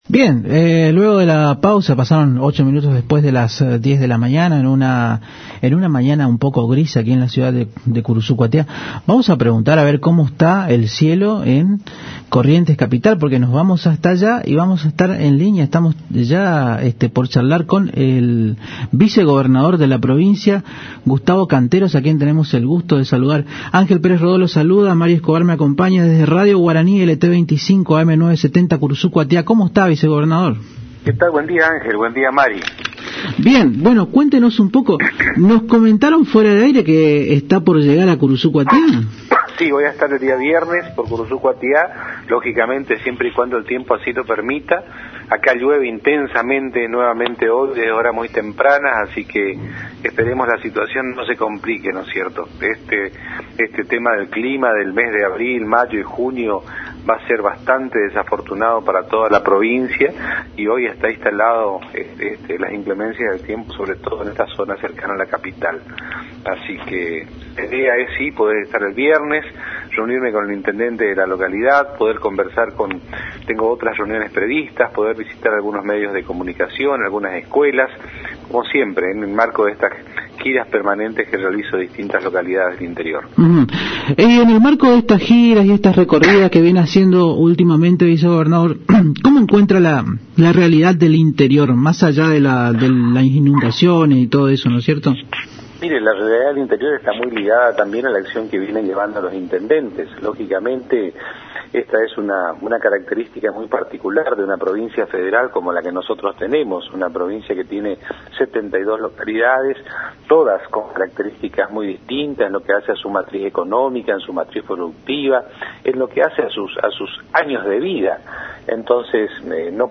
"Depende del tiempo y del clima", dijo en contacto con la AM 970 Radio Guarani refiriéndose a la visita a la ciudad que nació con la patria, añadiendo que se reunirá con el Jefe Comunal Ernesto Domínguez, y además con otros actores sociales de la ciudad, como asociaciones empresariales, y diversos consejos e instituciones que son pilares de la sociedad curuzucuateña.
Gustavo Canteros - Vice Gobernador.mp3